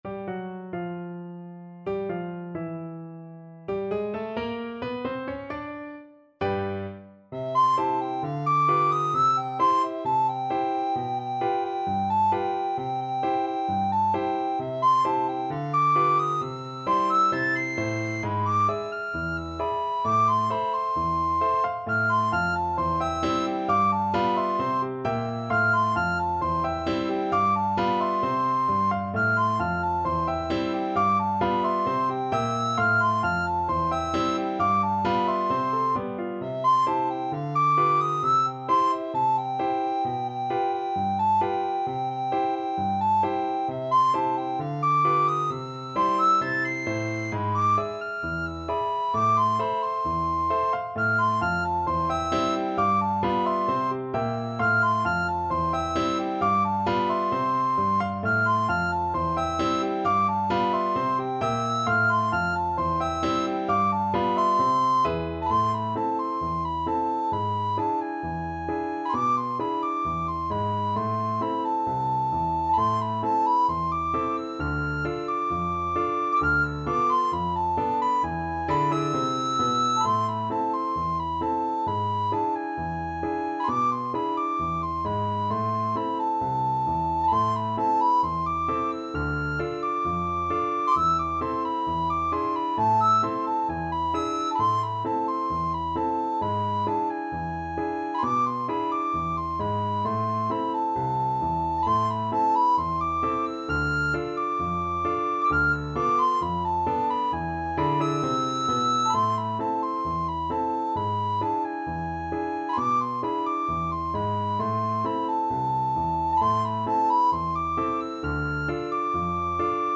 Jazz Scott Joplin Magnetic Rag Soprano (Descant) Recorder version
4/4 (View more 4/4 Music)
= 132 Allegretto ma non troppo
C major (Sounding Pitch) (View more C major Music for Recorder )
Jazz (View more Jazz Recorder Music)